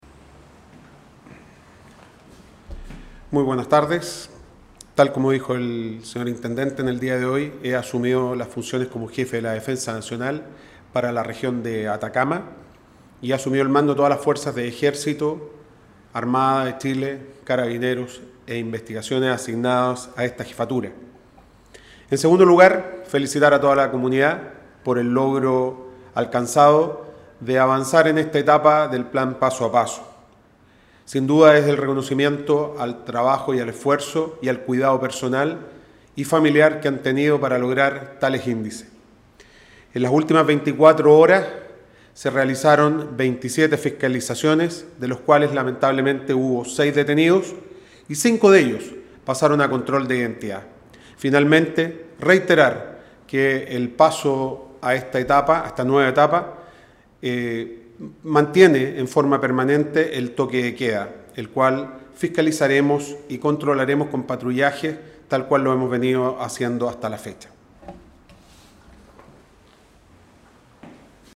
En el punto de prensa además se anunció el cambio del Jefe de la Defensa Nacional.